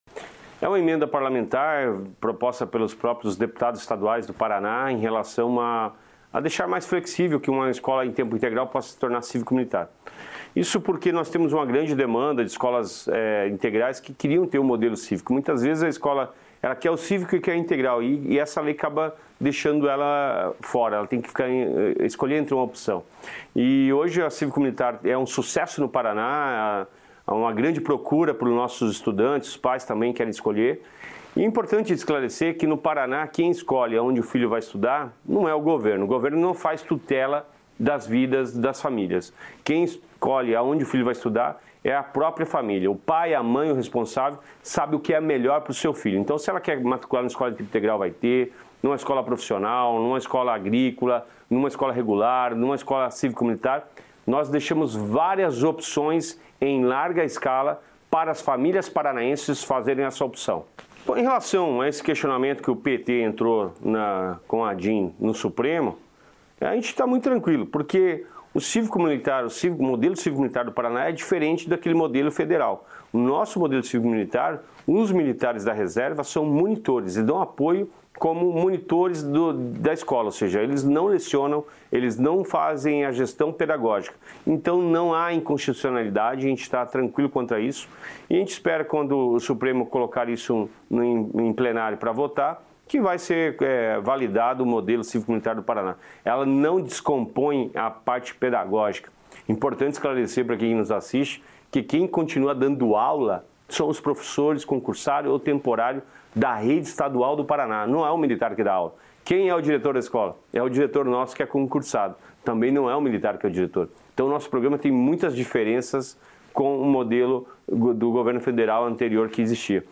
Sonora do secretário estadual da Educação, Roni Miranda, sobre escolas em tempo integral que podem aderir ao modelo cívico-militar